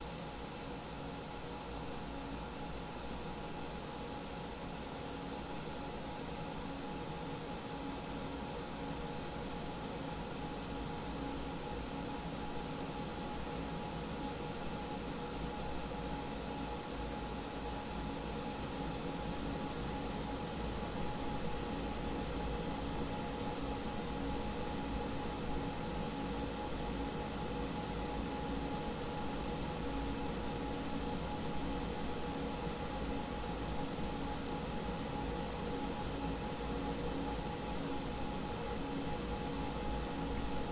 响亮的风扇
描述：风扇吹得很响
标签： 吹塑 风扇
声道立体声